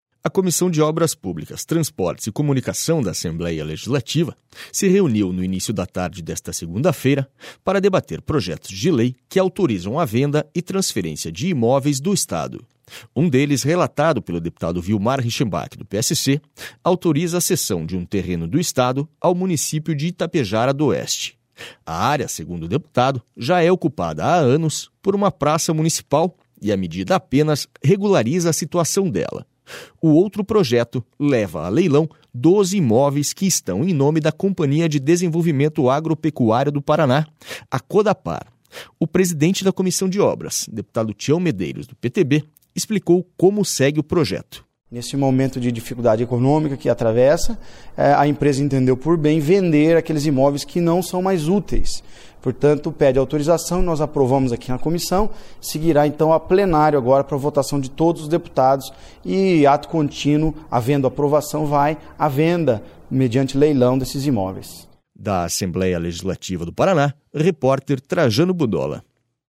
SONORA TIÃO MEDEIROS